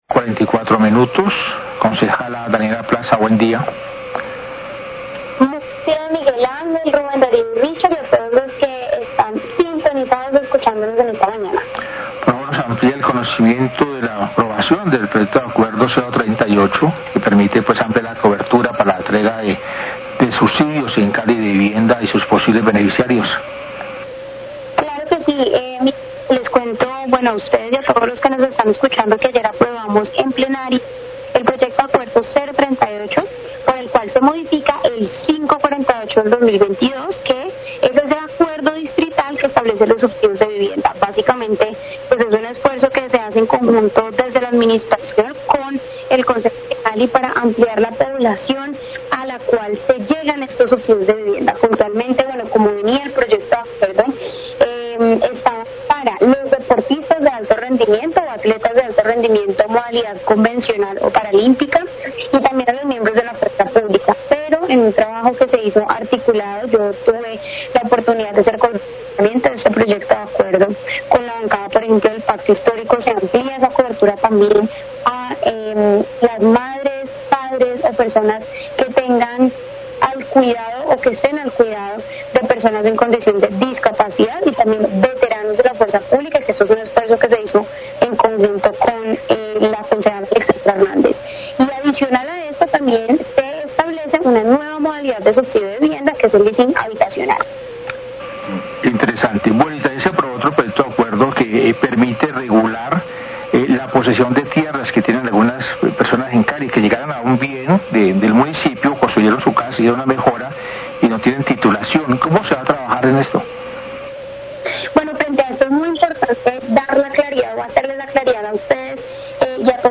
Radio
Concejal Daniella Plaza habla acerca del proyecto de acuerdo 038 que se aprobó en segundo debate y que autoriza la entrega de un subsidio distrital de vivienda a población diferenciada, beneficiando a deportistas de alto rendimiento y a la fuerza pública.